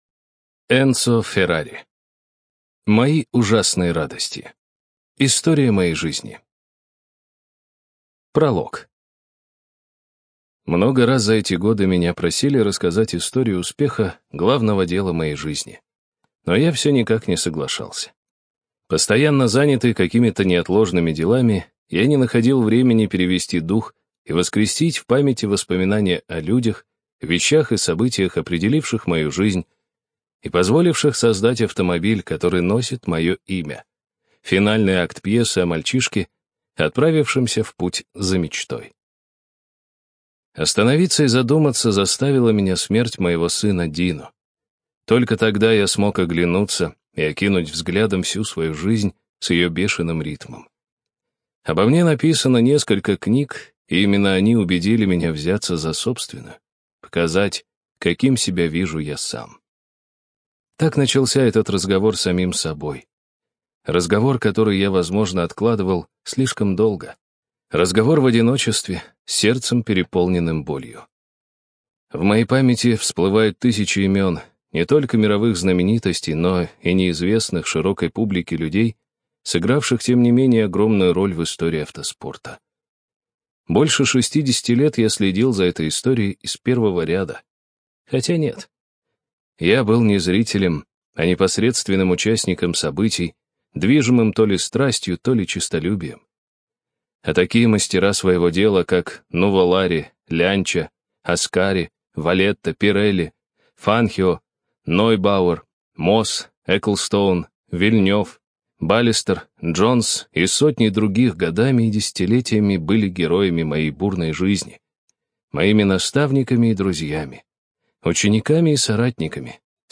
ЖанрБиографии и мемуары